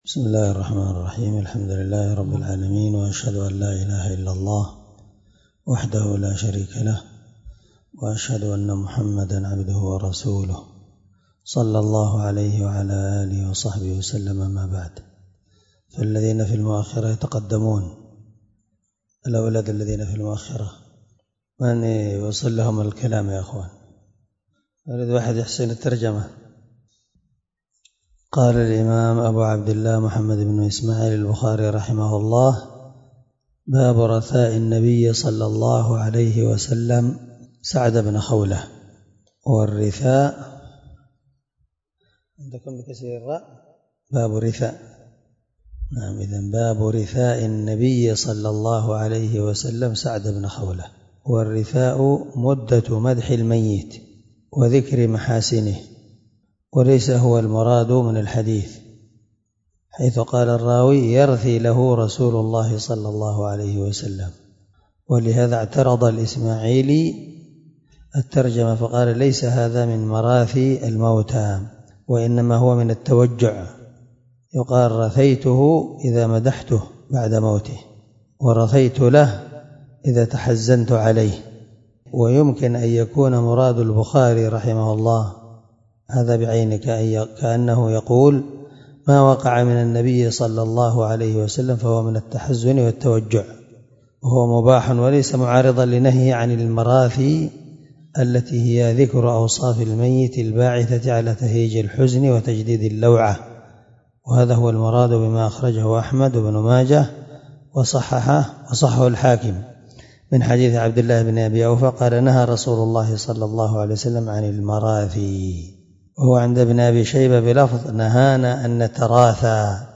749الدرس 22من شرح كتاب الجنائز حديث رقم(1295 )من صحيح البخاري